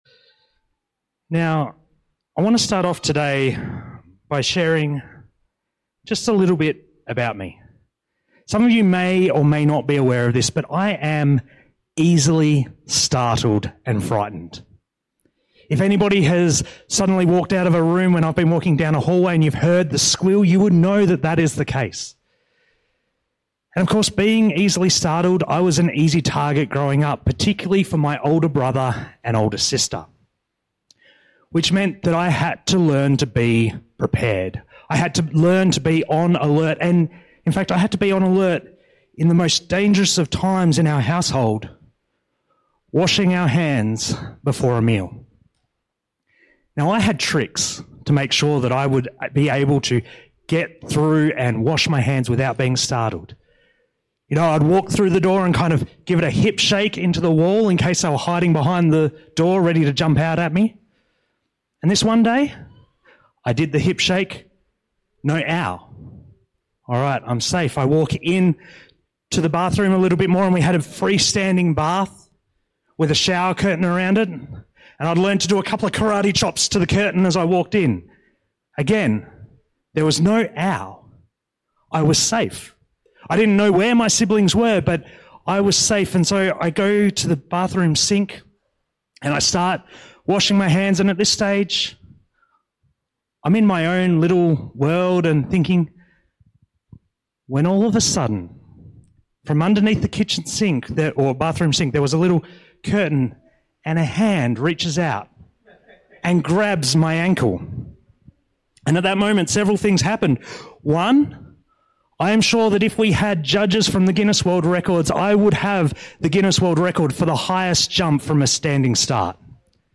Sermons – Alice Springs Baptist Church